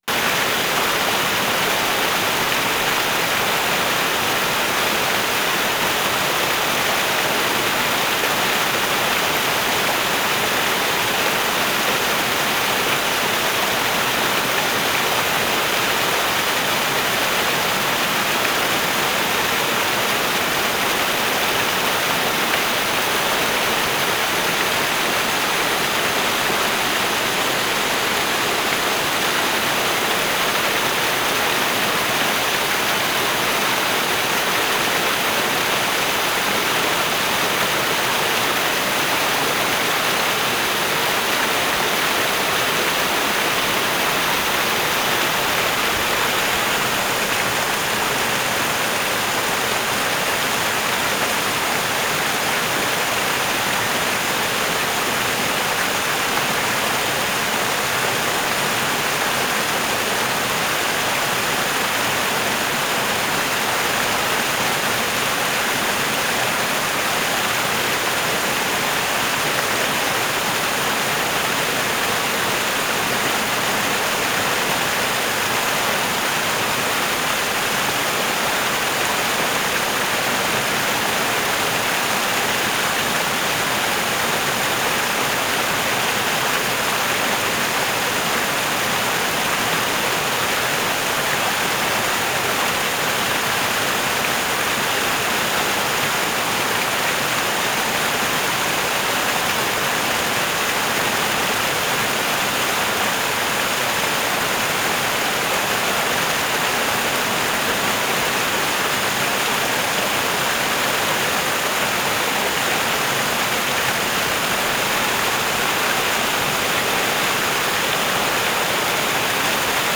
HORSESHOE BAY, SURROUNDING AREA NOV. 3, 1991
small rapids 2:00
9. broadband rush of water, little variation